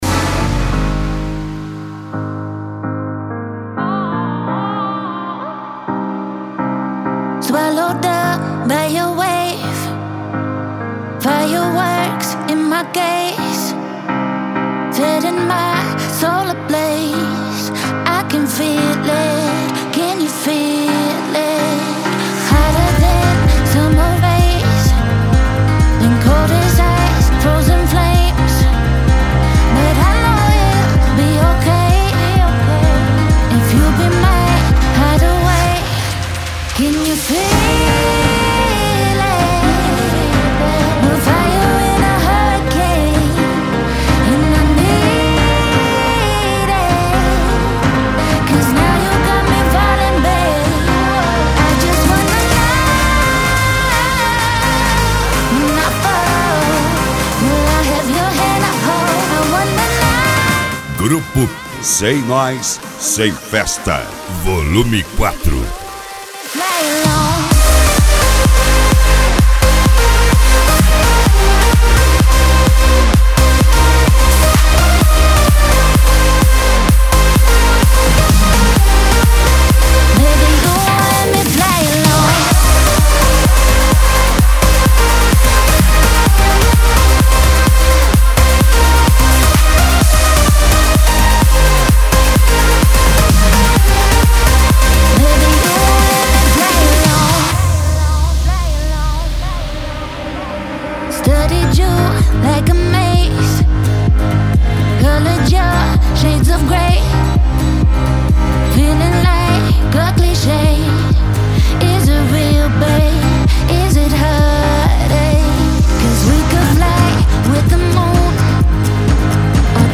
Eletronica
Funk
Funk Nejo
Mega Funk